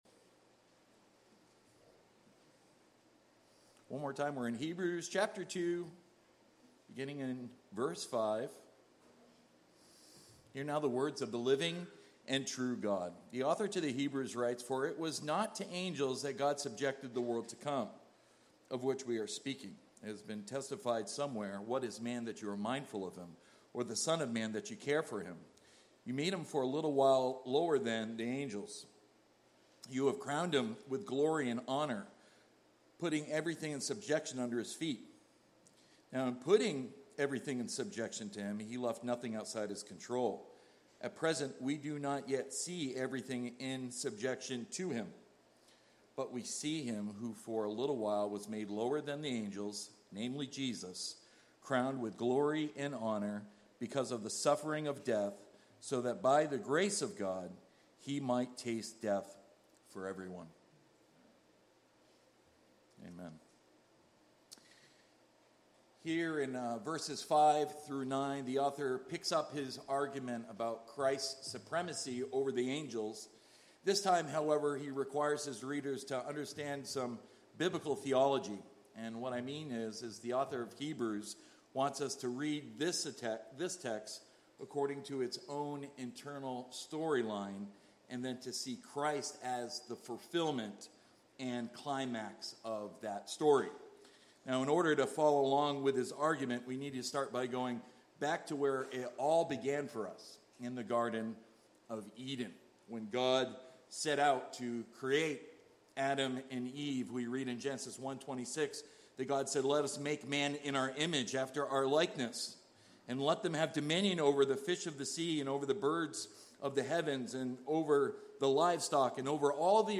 Sermons by At the Cross